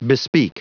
Prononciation du mot bespeak en anglais (fichier audio)
Prononciation du mot : bespeak